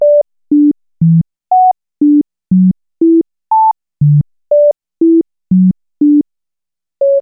Její tóny (tj. C, D, E, ...) jsou správně, ale jsou náhodně rozmístěny po třech různých oktávách.
rozhazeny.wav